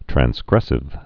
(trăns-grĕsĭv, trănz-)